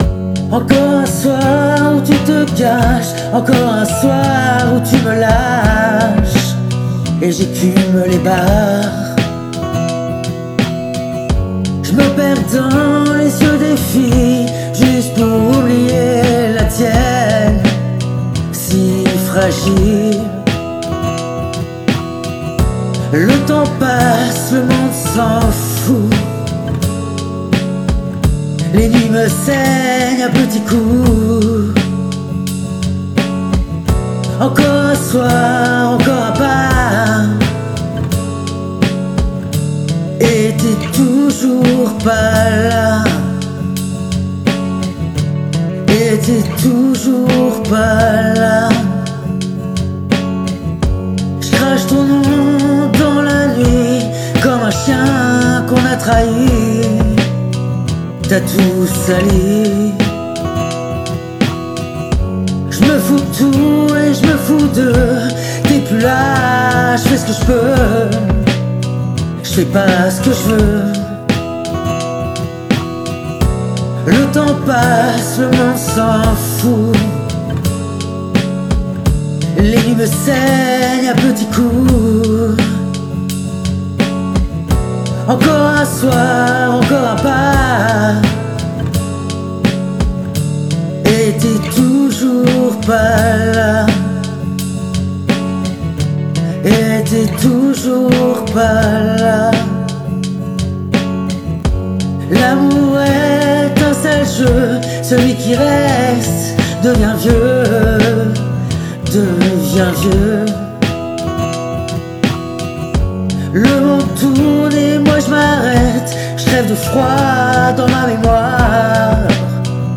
Sans production lourde.